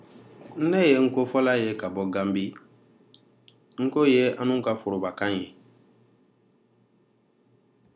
Dialogue: [nko]ߒߞߏ ߞߊ߲ ߝߐ[/nko]
This is a dialogue of people speaking Nko as their primary language.